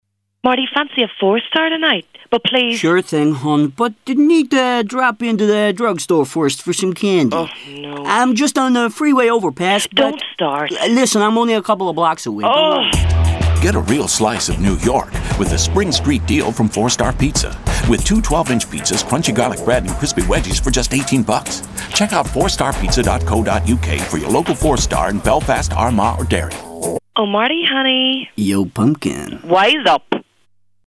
Husky tones, gravelly voice, deep timber, mature, baritone, sexy
Sprechprobe: Sonstiges (Muttersprache):